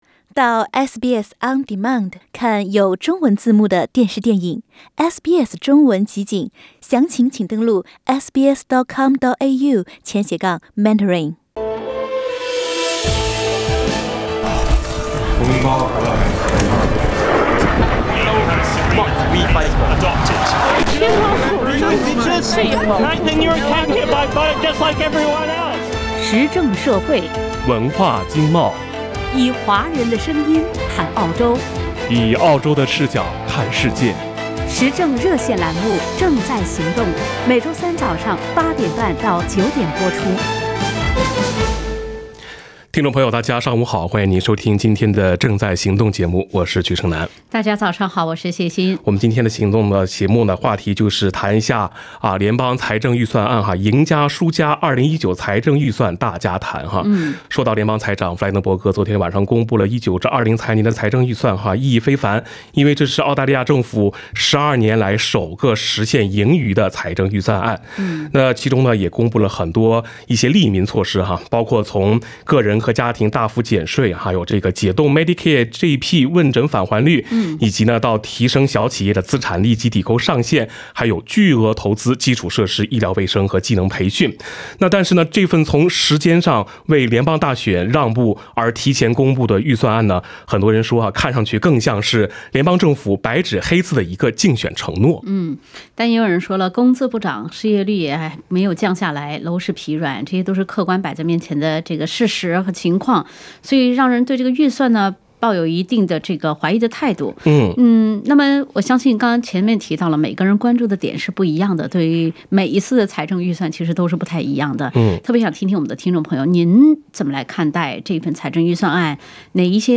普通话听众分享预算读后感，预算承诺几多成真？